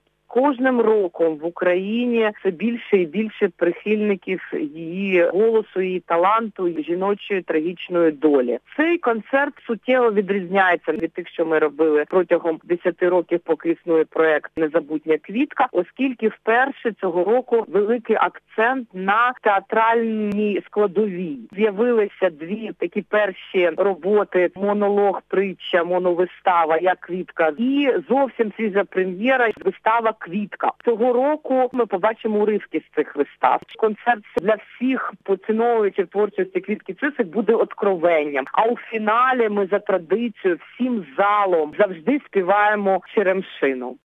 Прослухати репортаж Будь у курсі - слухай новини!